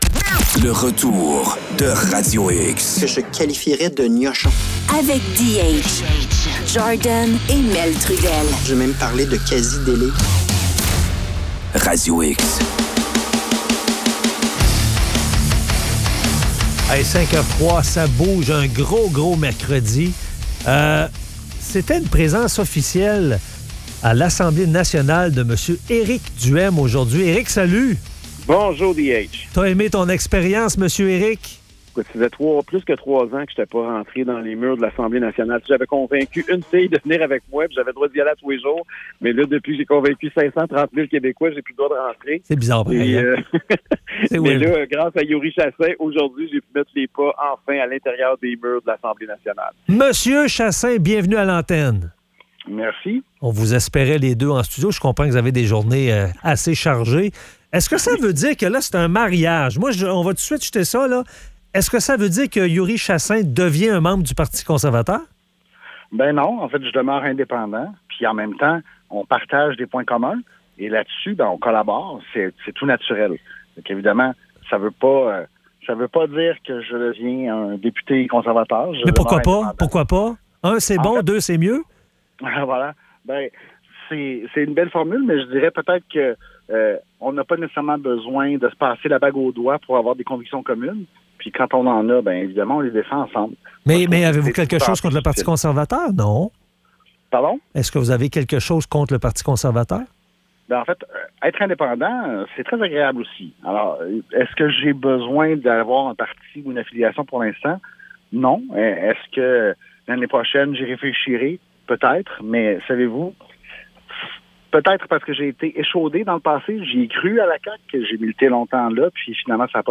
Entrevue avec Éric Duhaime et Youri Chassin.